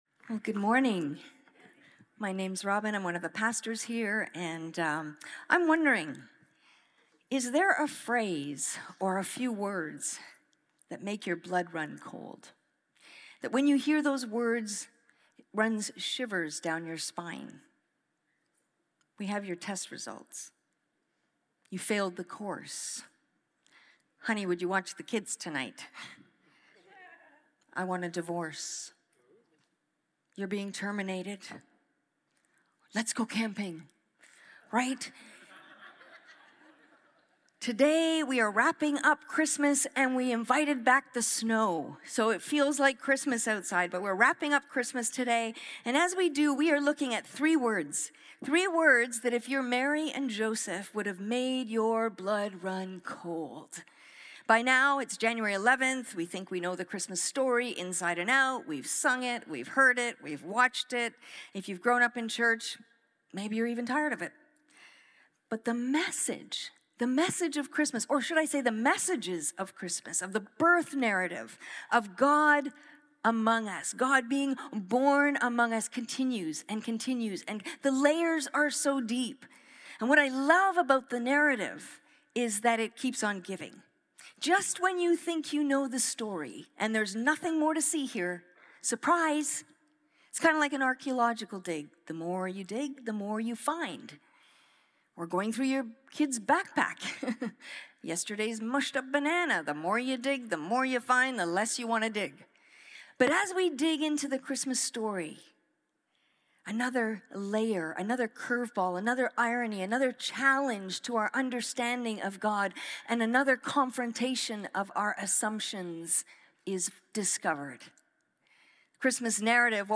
The Christmas story doesn’t end with angels and manger scenes - it continues into fear, flight, and forced exile. When Joseph hears the words “escape to Egypt,” God overturns assumptions about safety, enemies, and where refuge can be found. This message